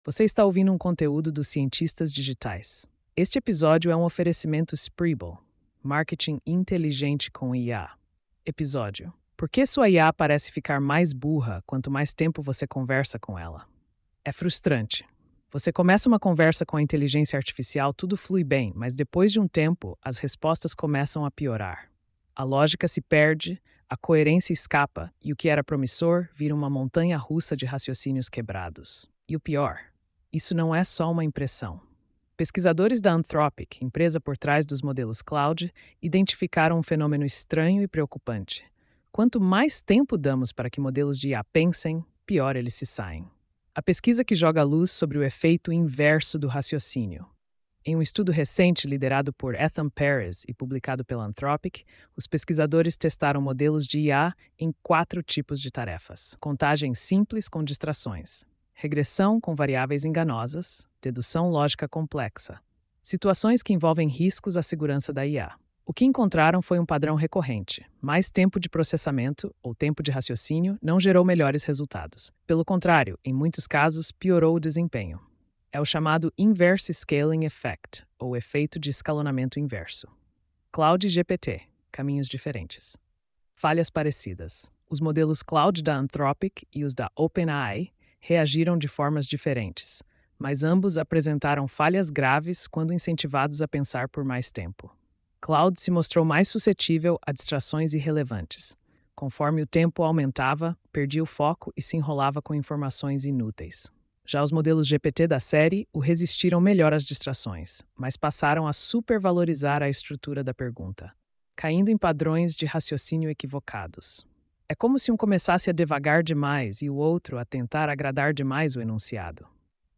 post-3459-tts.mp3